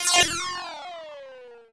retro_enemy_attack_01.wav